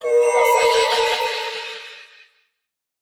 Minecraft Version Minecraft Version snapshot Latest Release | Latest Snapshot snapshot / assets / minecraft / sounds / mob / allay / idle_without_item4.ogg Compare With Compare With Latest Release | Latest Snapshot